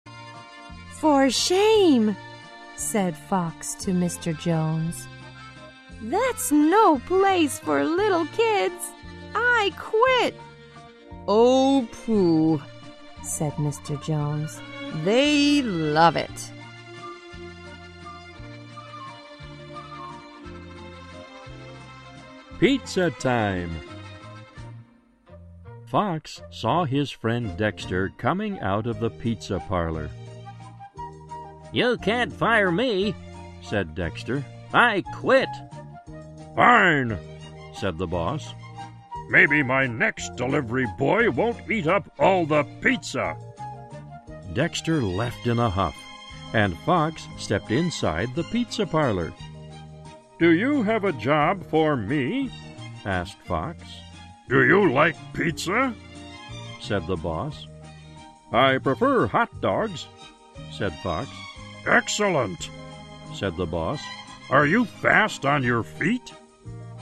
在线英语听力室小狐外传 第80期:披萨的听力文件下载,《小狐外传》是双语有声读物下面的子栏目，非常适合英语学习爱好者进行细心品读。故事内容讲述了一个小男生在学校、家庭里的各种角色转换以及生活中的趣事。